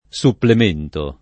Supplem%nto] s. m. — antiq. supplimento [Supplim%nto]: il supplimento delle parole che non potevano arrivar lassù [